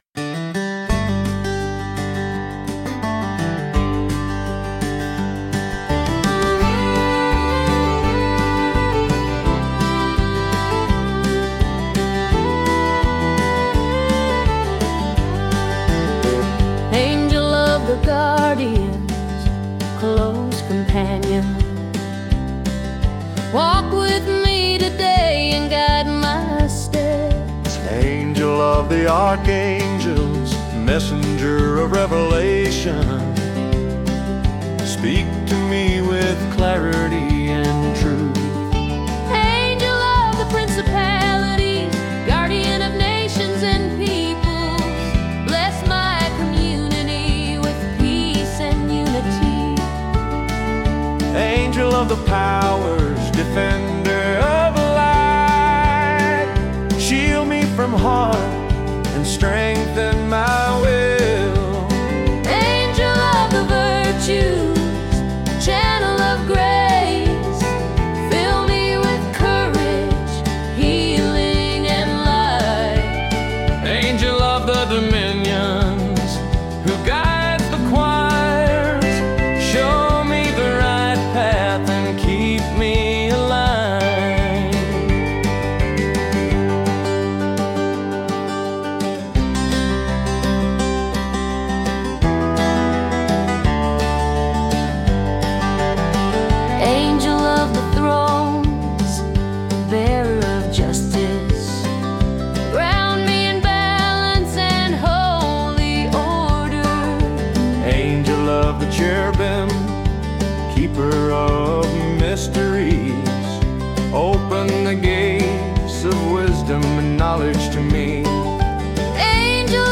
It’s country music with wings.